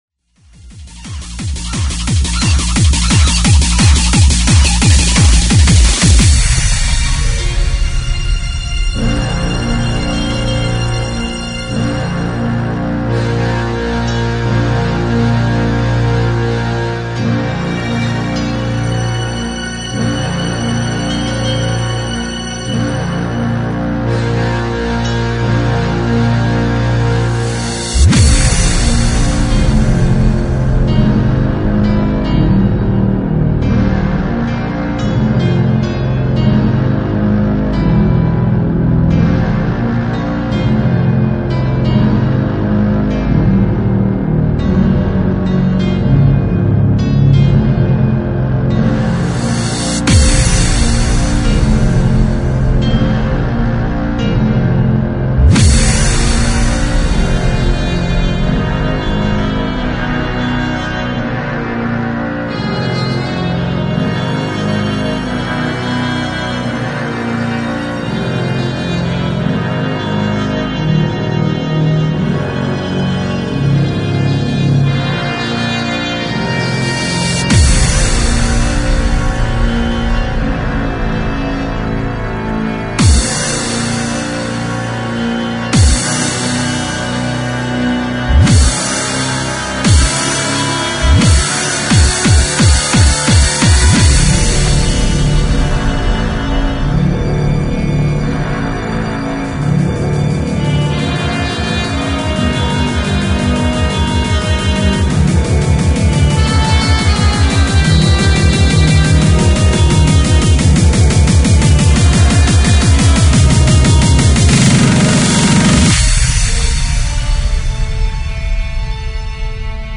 Freeform/Hardcore
Recorded at Kreatrix, Ottawa, Canada.